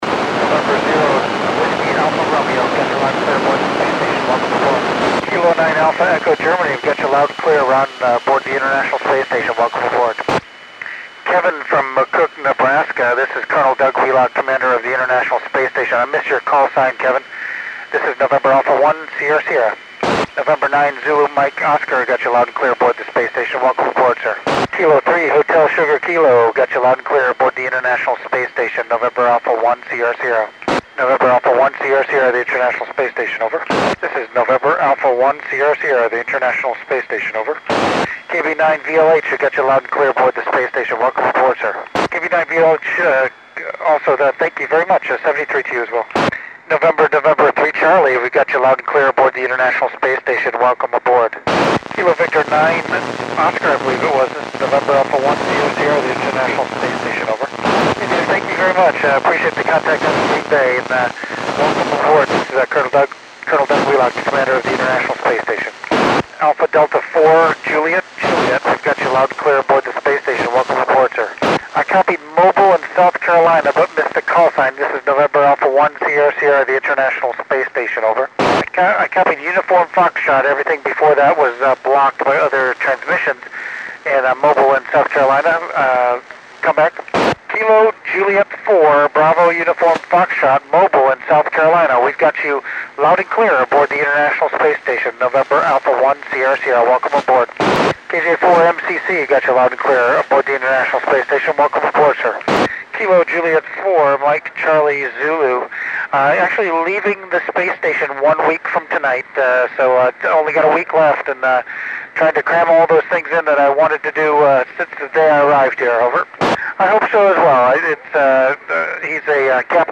NA1SS (Col. Doug Wheelock) working U.S. stations on 18 November 2010 at 2116 UTC